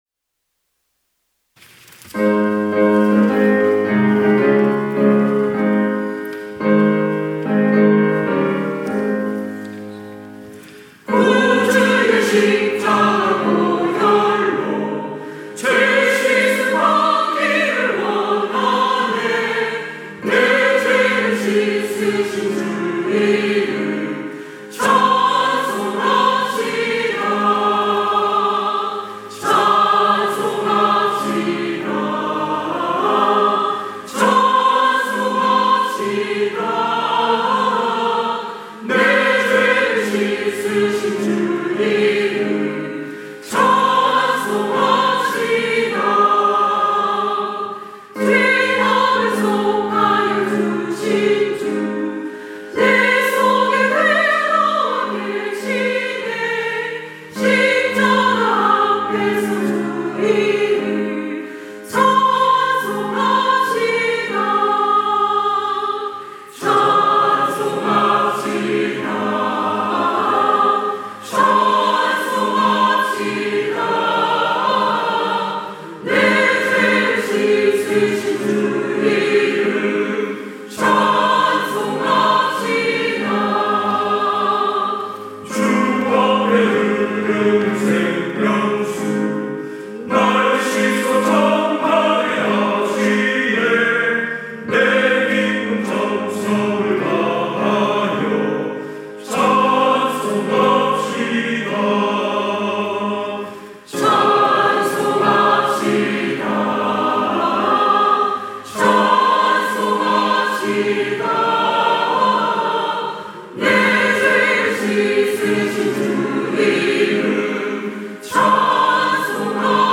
찬양대 가브리엘